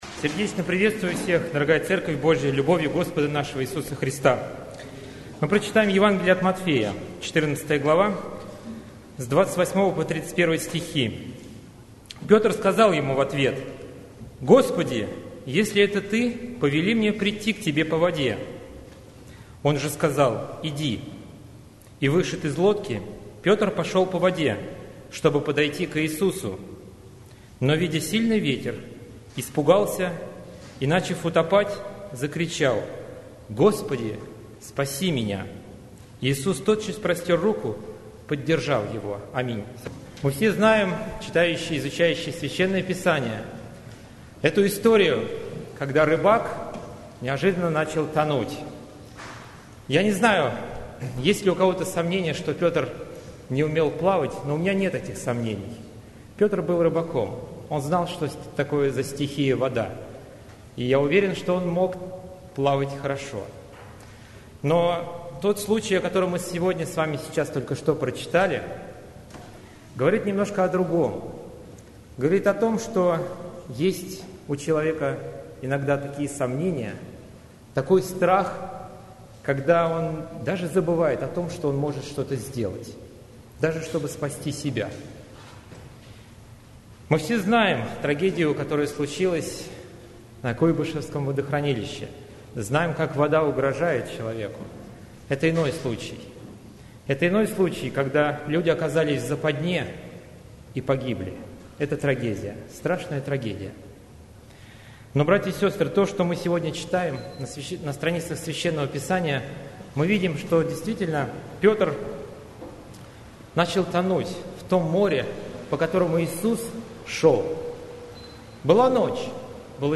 Церковь: Московская Центральная Церковь ЕХБ (Местная религиозная организация "Церковь евангельских христиан-баптистов г. Москвы")